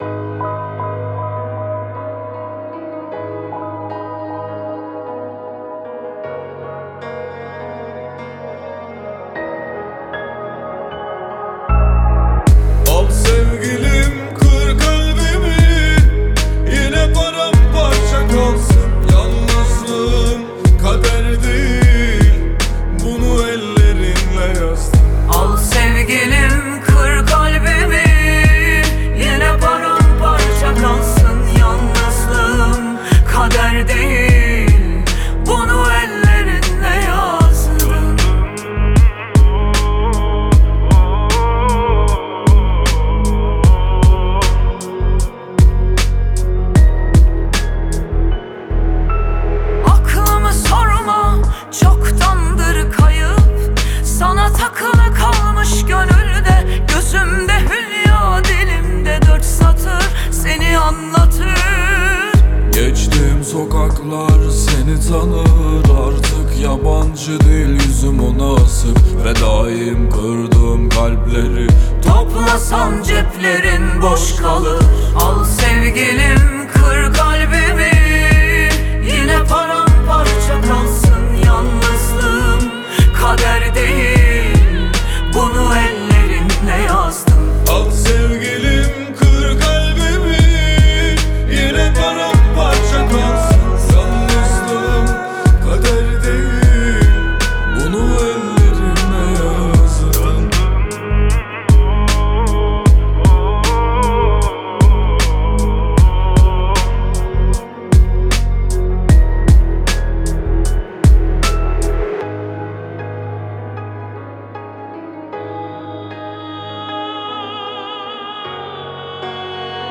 آهنگ ترکیه ای آهنگ غمگین ترکیه ای آهنگ هیت ترکیه ای ریمیکس
آهنگ مشترک